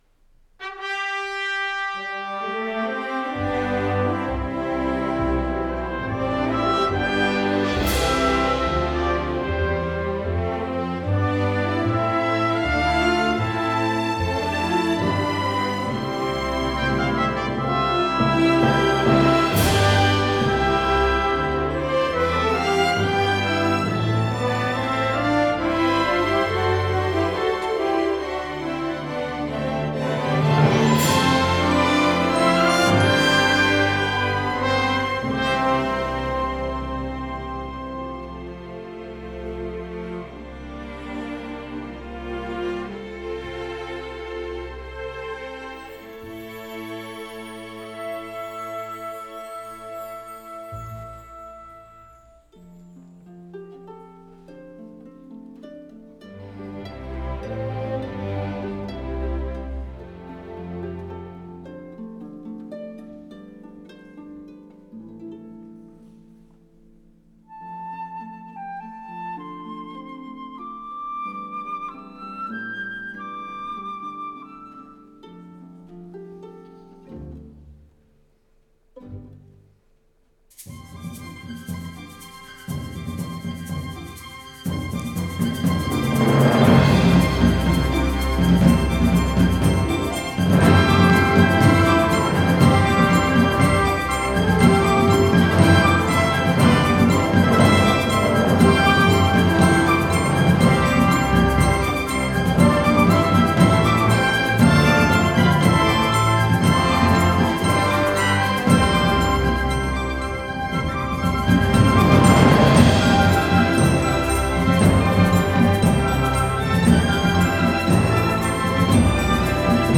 Live-Performance